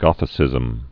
(gŏthĭ-sĭzəm)